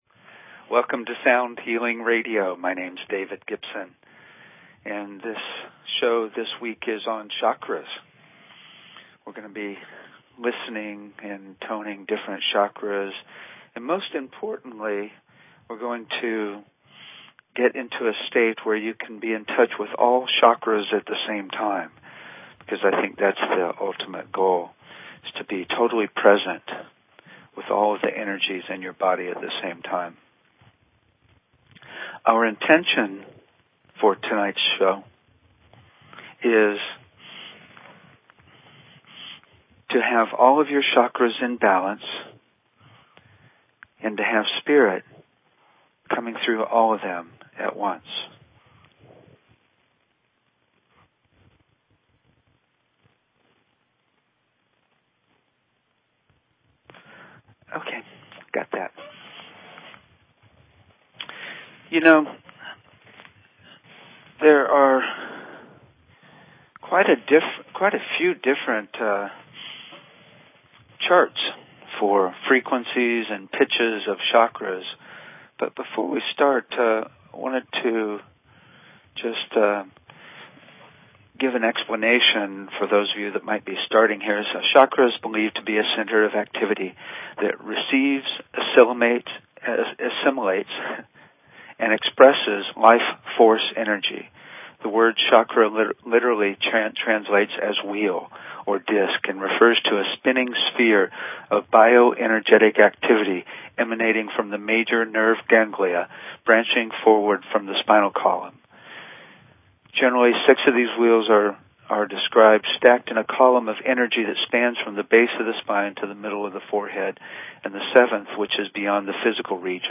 Talk Show Episode, Audio Podcast, Sound_Healing and Courtesy of BBS Radio on , show guests , about , categorized as
We also do a very powerful Chakra Meditation where we have you tune into all 7 chakras at the same time.